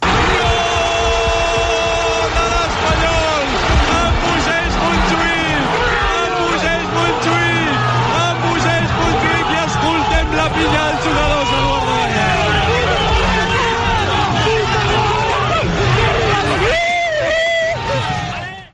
Narració feta des de l'Estadi Lluís Companys de Barcelona
Esportiu